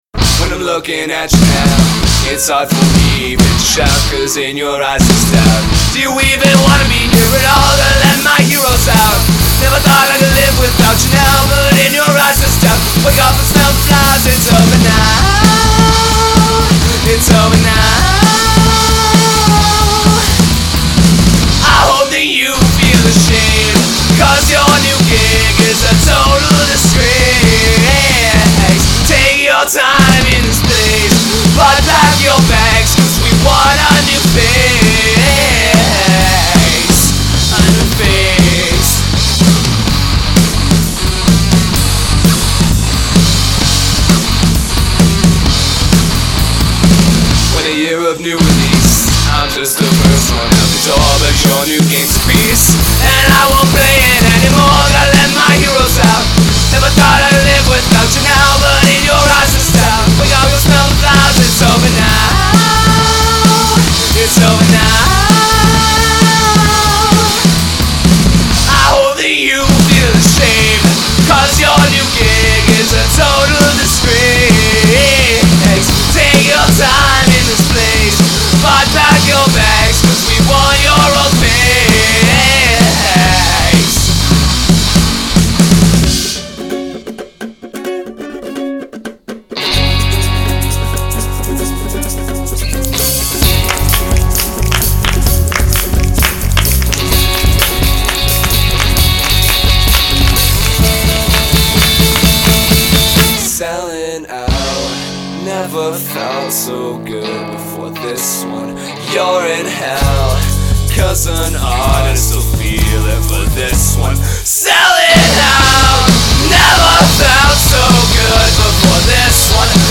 Genre Hop
i really like your drums on this song.